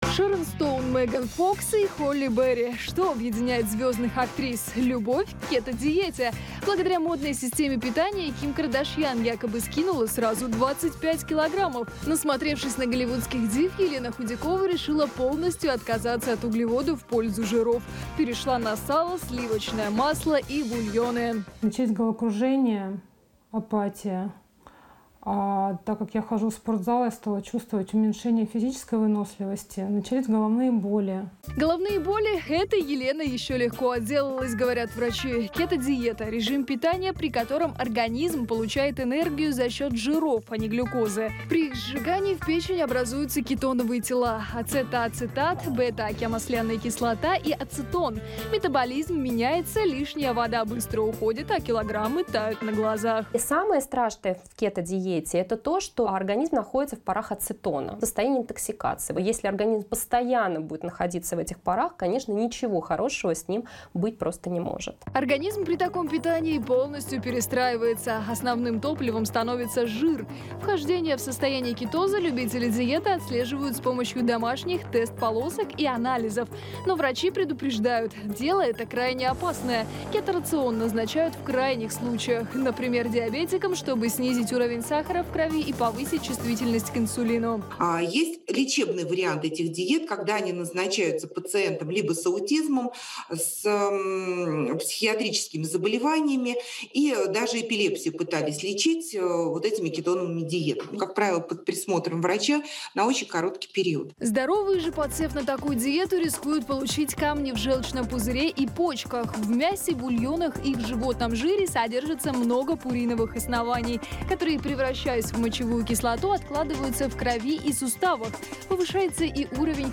Источник: телеканал ТВЦ